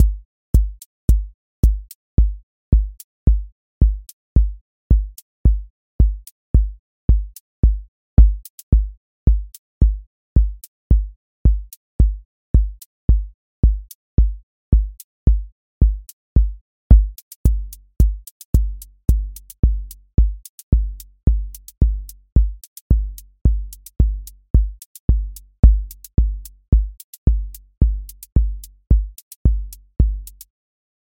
QA Listening Test house Template: four_on_floor
steady house groove with lift return
• voice_kick_808
• voice_hat_rimshot
• voice_sub_pulse